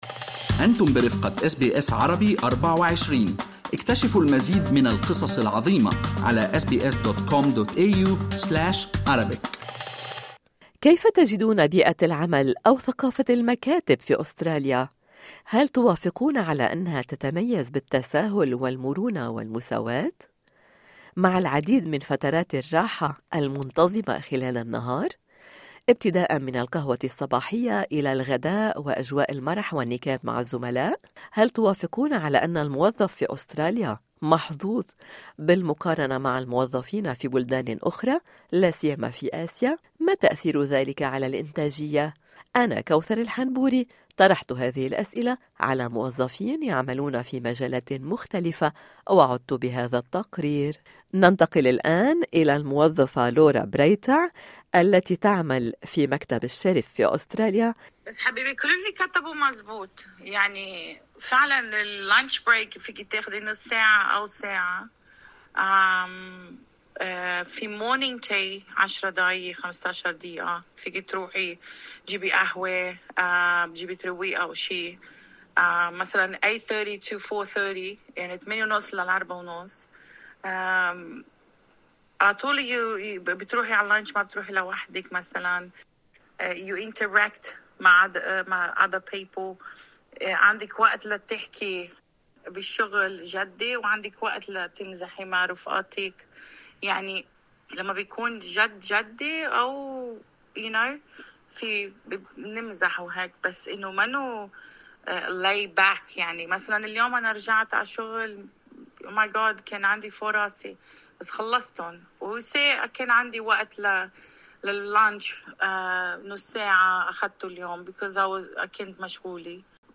ما تأثير ذلك على الأنتاجية؟ طرحنا هذه الأسئلة على موظفين في المكاتب الأسترالية يعملون في مجالات مختلفة.